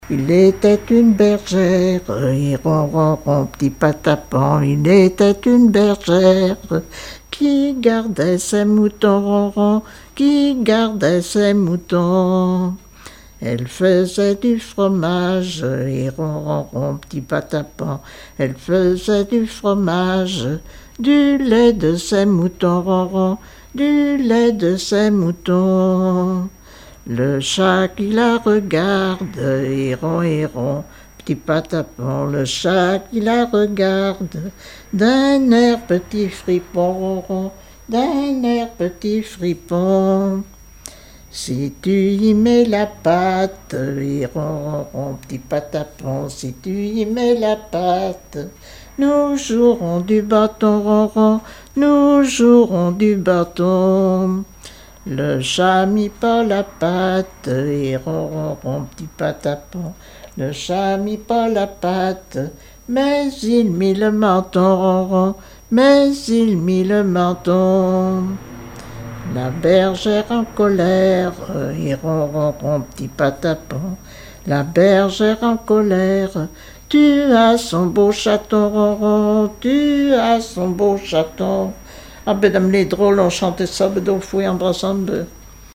Genre laisse
Témoignages et chansons
Catégorie Pièce musicale inédite